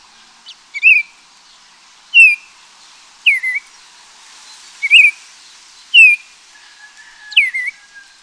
Red-crested Cardinal
Paroaria coronata